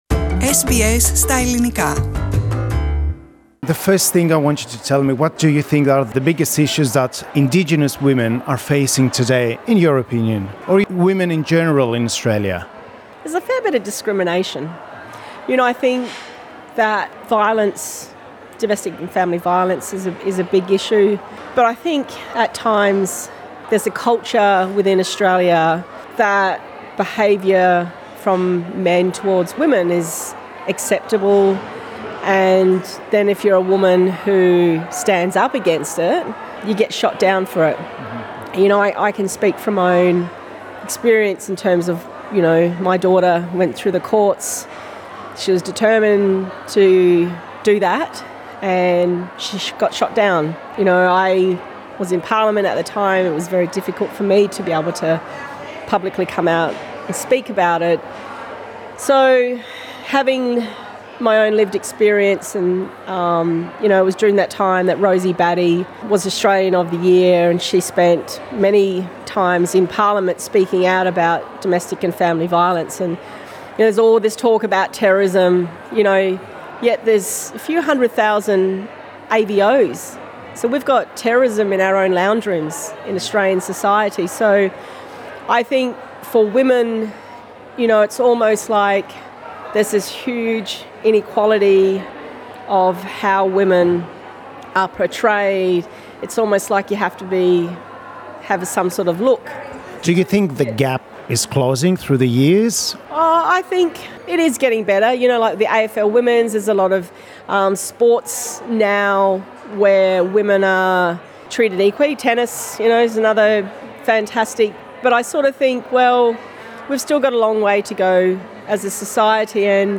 She talked to SBS Greek.